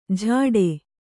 ♪ jhāḍe